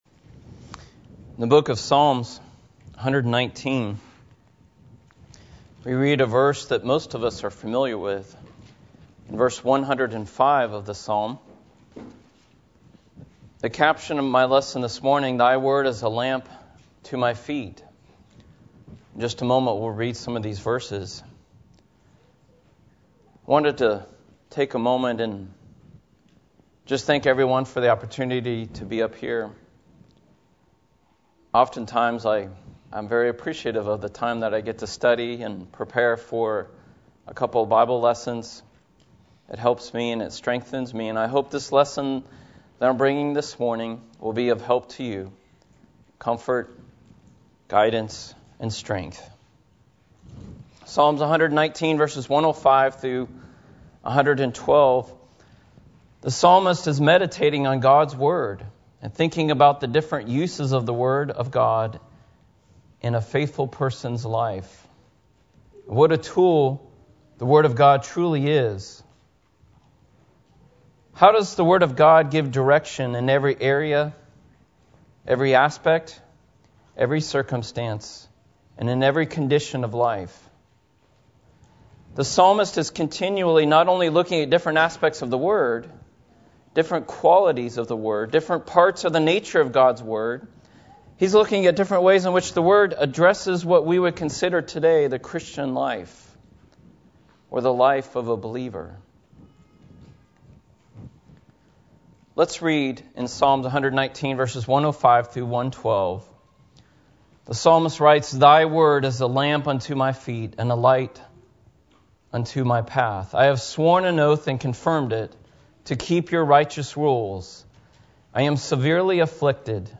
Lesson One: